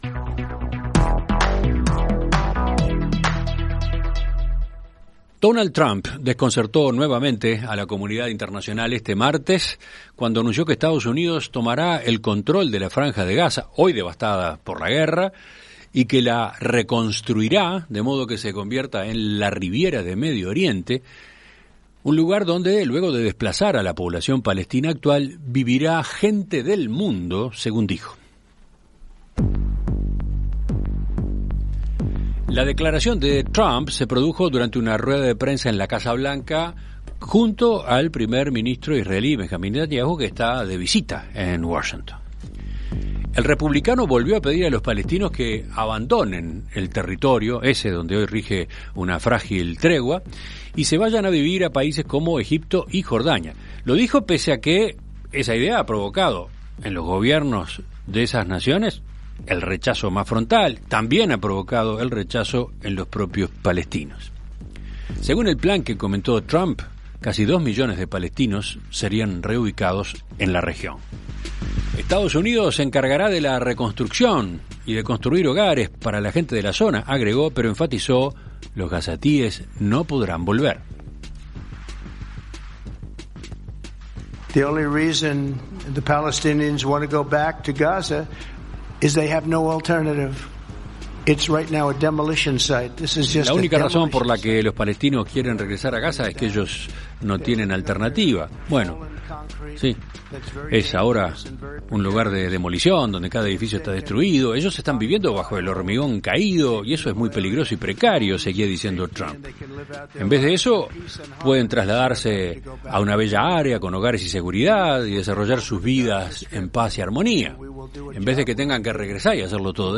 En Perspectiva Zona 1 – Entrevista Central: Elbio Roselli - Océano
Conversamos con el Dr. Elbio Roselli, embajador retirado, ex presidente del consejo de seguridad de la ONU.